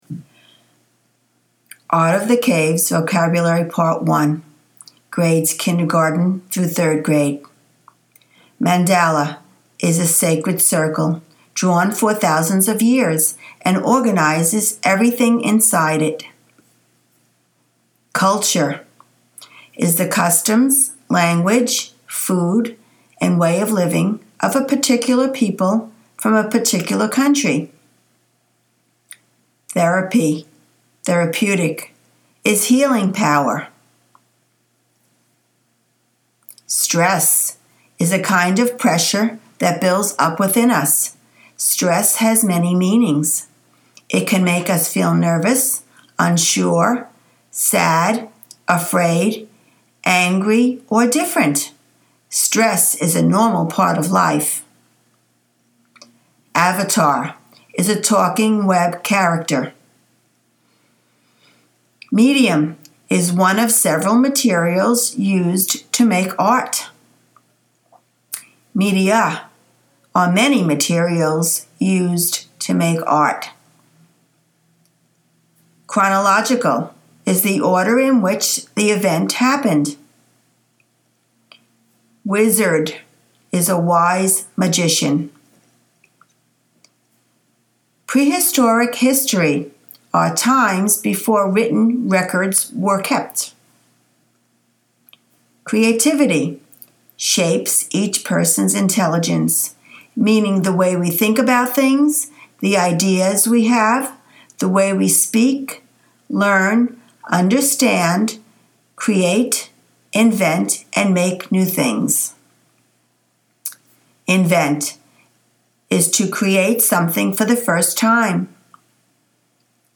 Vocabulary